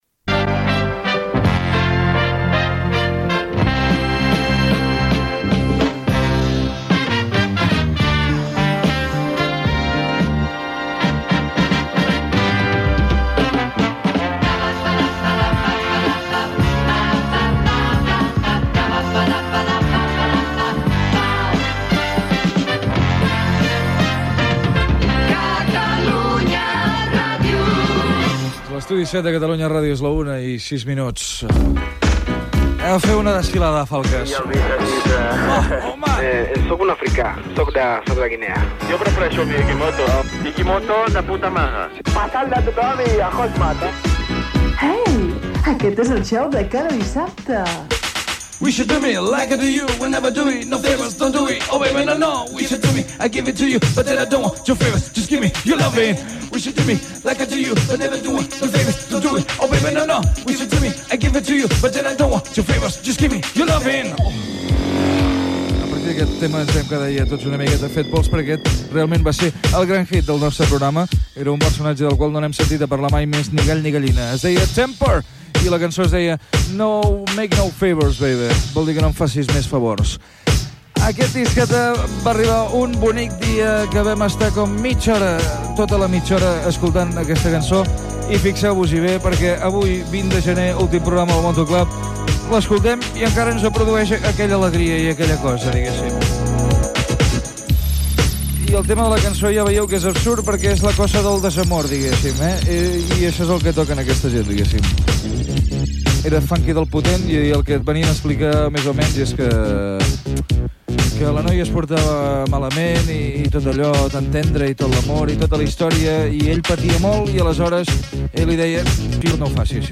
Sintonia de l'emissora, hora, carerta del programa, record d'un èxit musical del programa, comentaris diversos i tema musical.
Musical